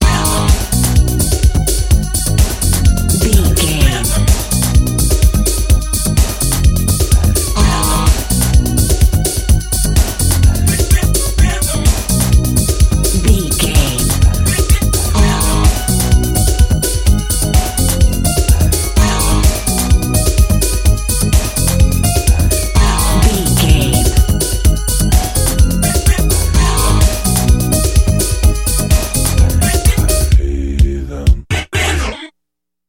Aeolian/Minor
E♭
synthesiser
Eurodance